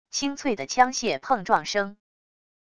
清脆的枪械碰撞声wav音频